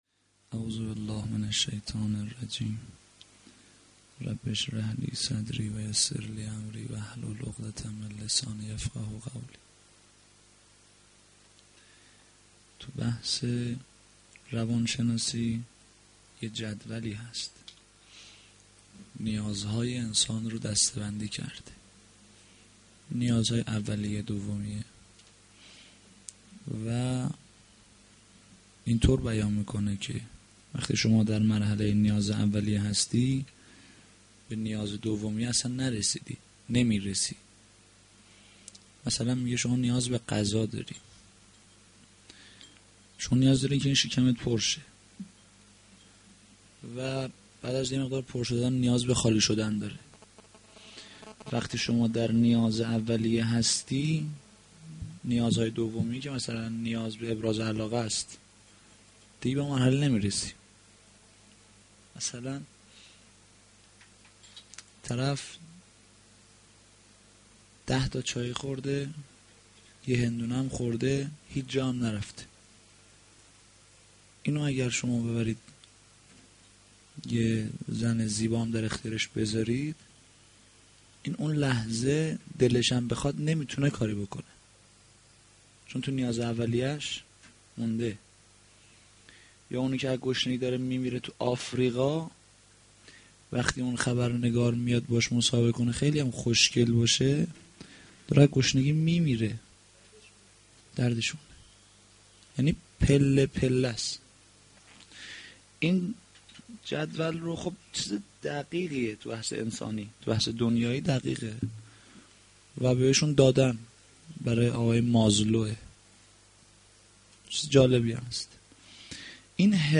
sh-4-moharram-92-sokhanrani.mp3